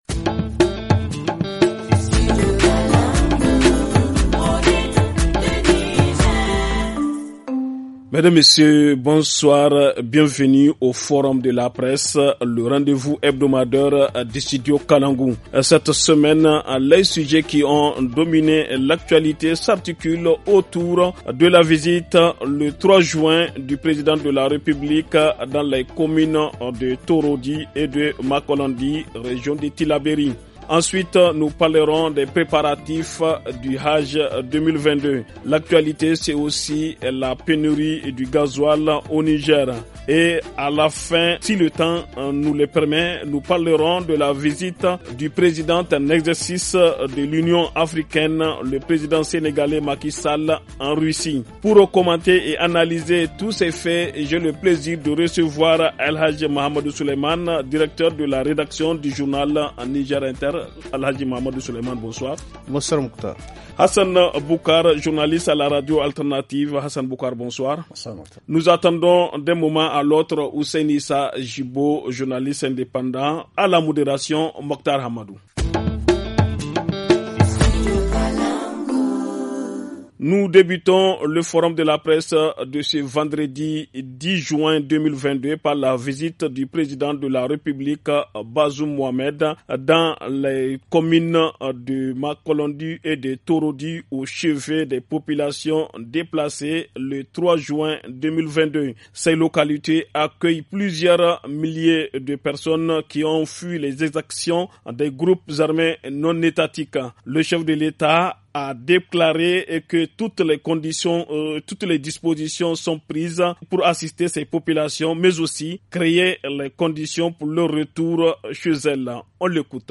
Pour commenter et analyser tous ces faits, nous avons reçu comme invités :